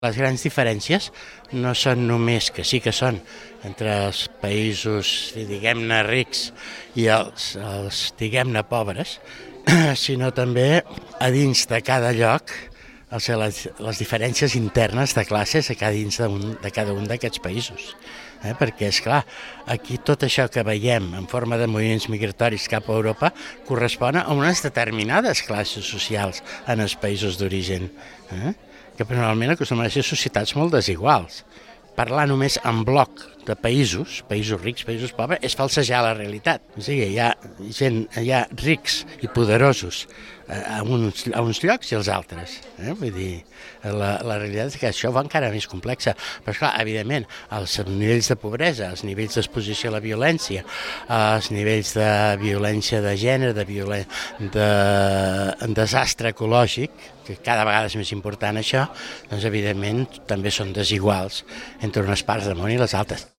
L’ONG, acusada per la justícia italiana d’afavorir la immigració il·legal, ha presentat la situació humanitària actual al mar Mediterrani. Ho ha fet en el darrer dels Vermuts CalaCultura de la temporada, que va omplir aquest diumenge al migdia el CIP.